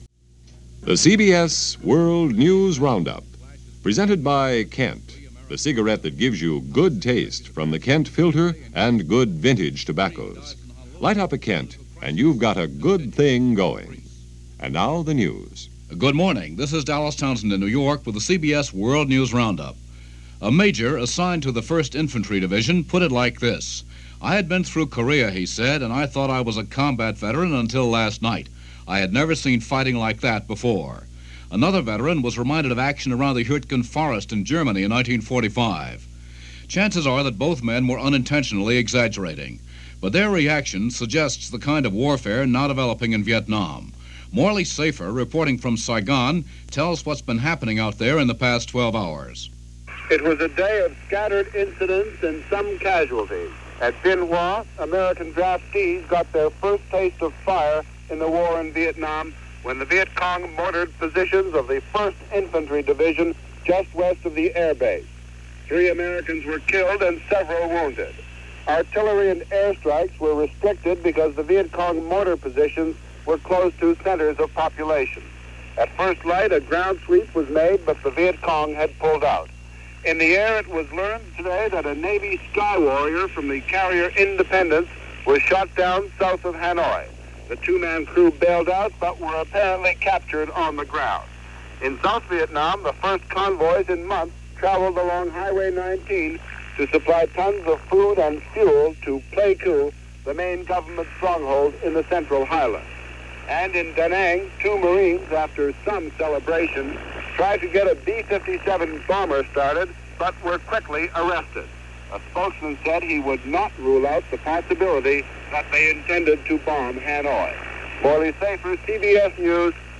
July 19,1965 - "Not Since Korea" - Vietnam Takes A Turn - Funeral For Adlai Stevenson - news for this day in 1965 - Past Daily.